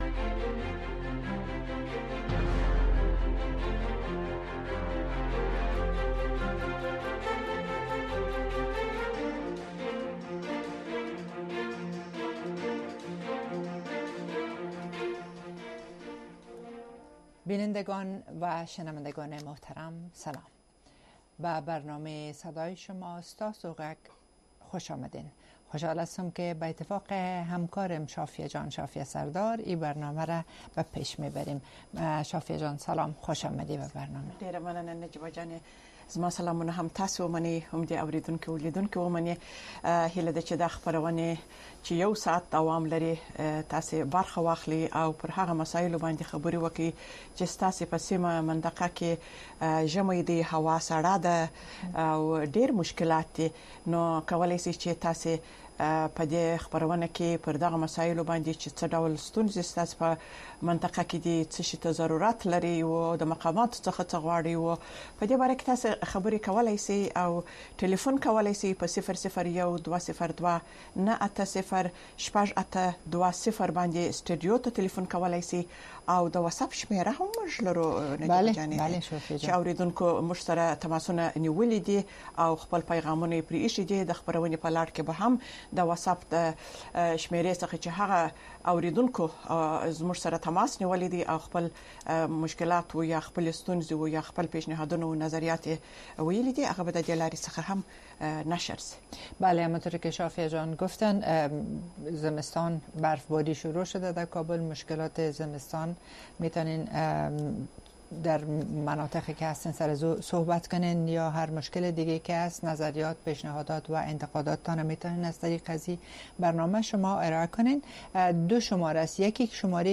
دا خپرونه په ژوندۍ بڼه د افغانستان په وخت د شپې د ۹:۳۰ تر ۱۰:۳۰ بجو پورې خپریږي.